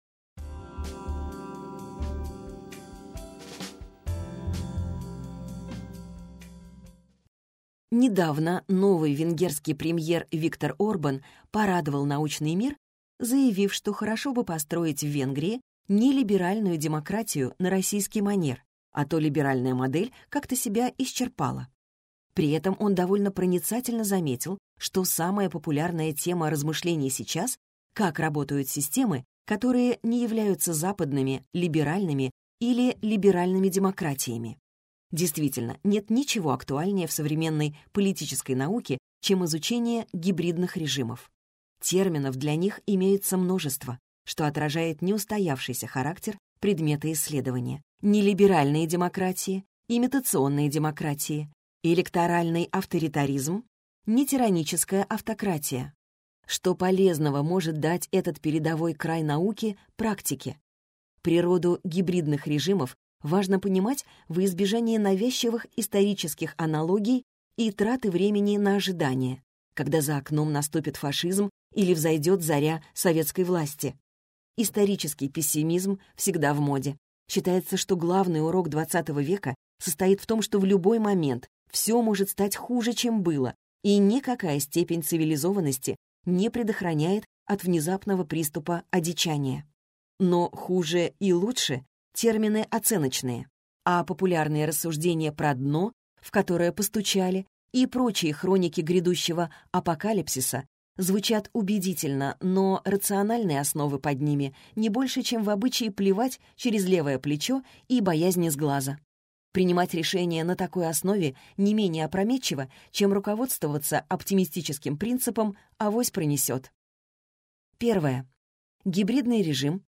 Аудиокнига Практическая политология. Пособие по контакту с реальностью | Библиотека аудиокниг